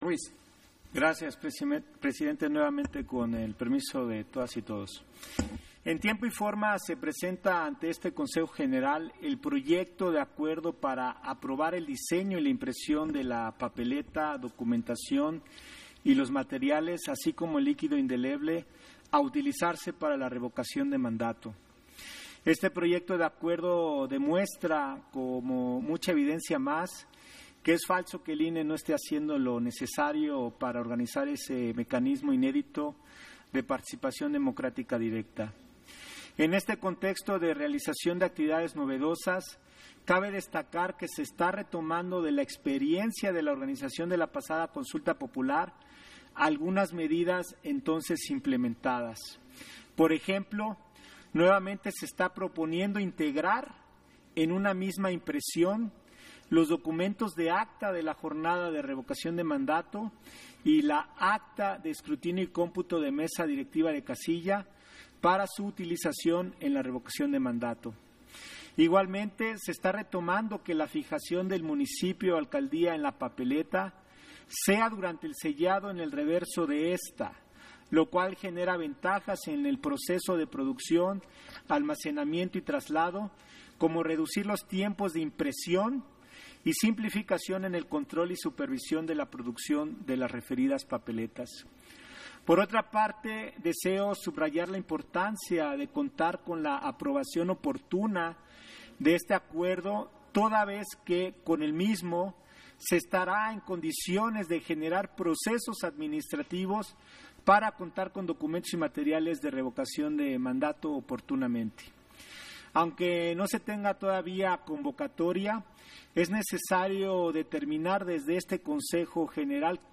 Intervención de José Roberto Ruiz, en Sesión Extraordinaria, en el punto en que se aprueba el diseño e impresión de material y liquido indelebre para las personas participantes durante la Revocación de Mandato